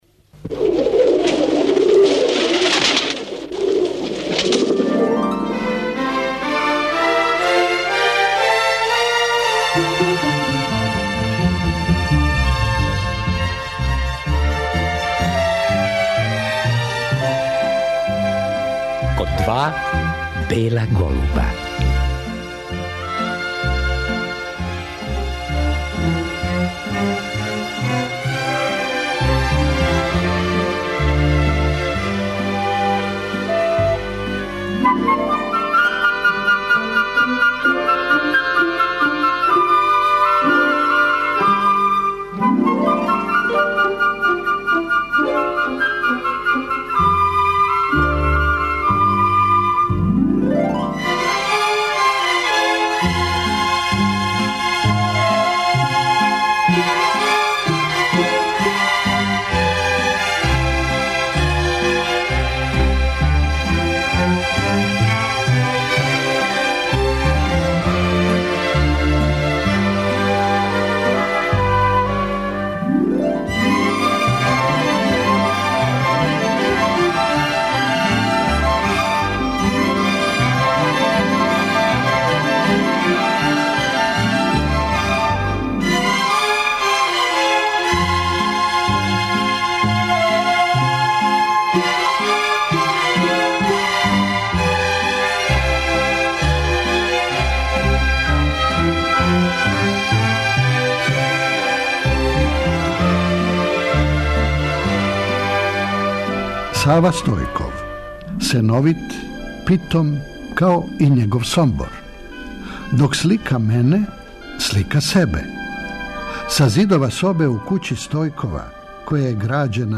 Сава Стојков, сликар бајковитих предела војвођанске равнице, говори о својој младости, годинама проведеним у неуморном учењу и стварању, о бројним изложбама и признањима које је добио за свој рад, о својој породици...
Гост емисије био је октобра 2013. године.